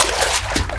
sfx_step_water_r.wav